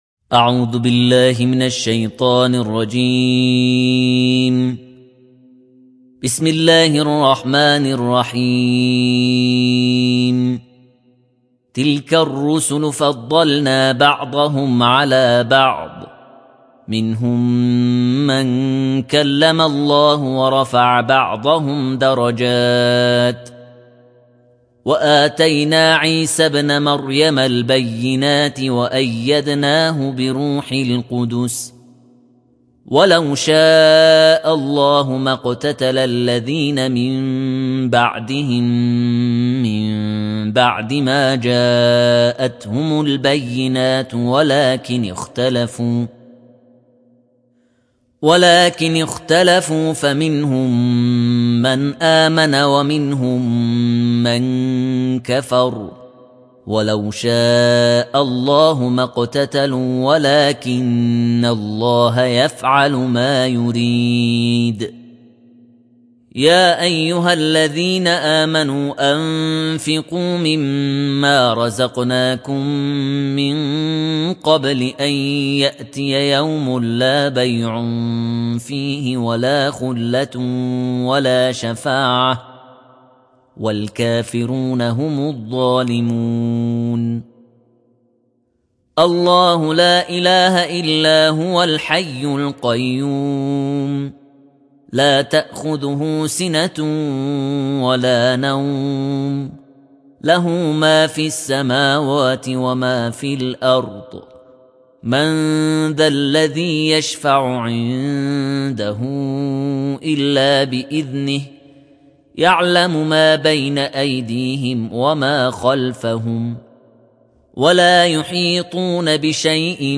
تلاوت جزء سوم قرآن